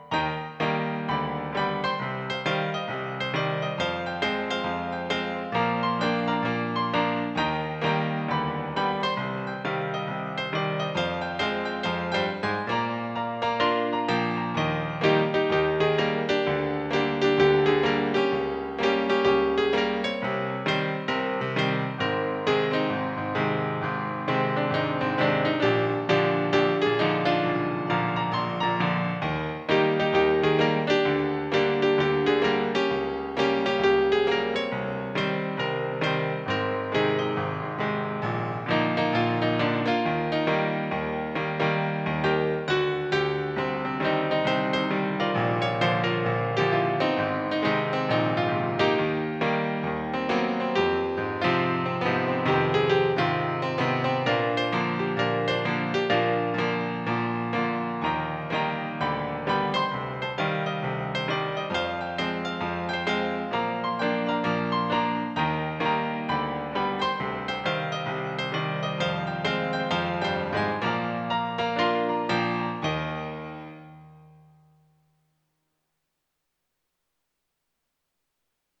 I've really become rusty with my piano skills.